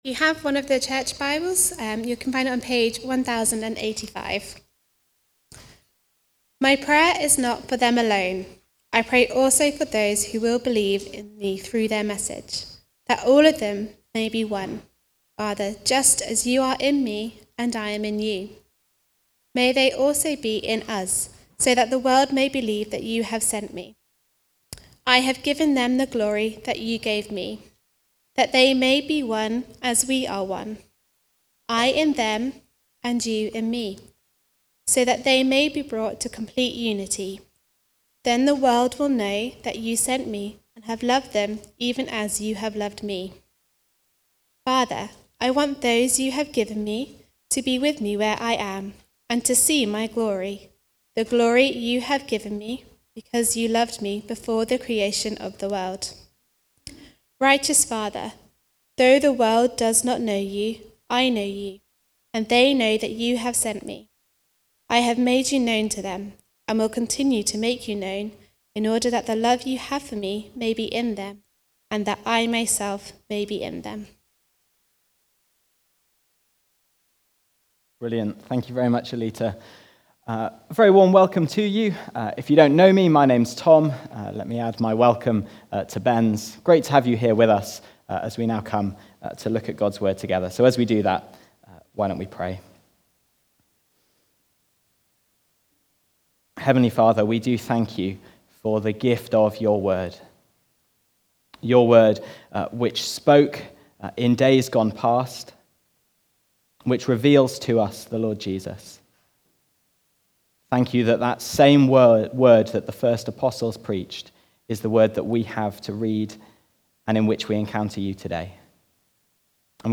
The Future (John 17:20-26) from the series Comfort and Joy. Recorded at Woodstock Road Baptist Church on 06 April 2025.